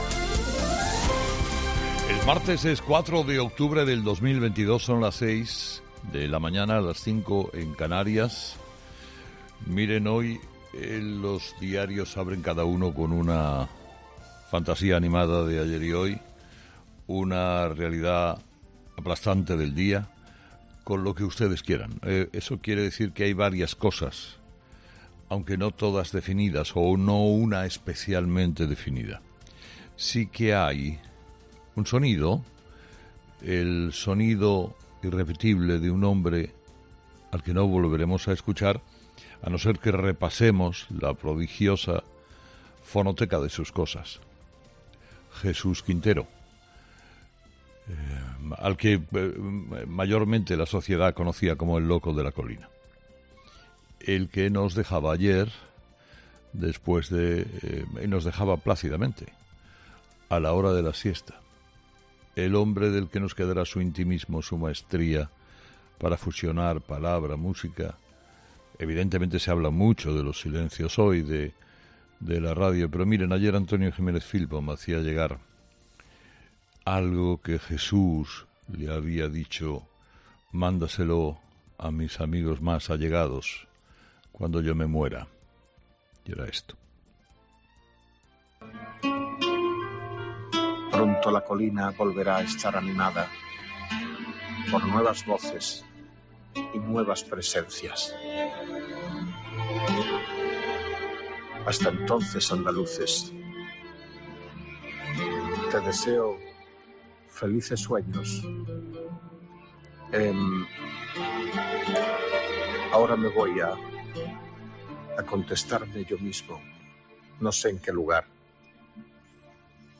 Carlos Herrera, director y presentador de 'Herrera en COPE', ha comenzado el programa de este martes analizando las principales claves de la jornada, que pasan, entre otros asuntos, por el fallecimiento a los 82 años del periodista Jesús Quintero, al que Carlos Herrera ha querido dedicar de forma íntegra su primer monólogo de esta mañana.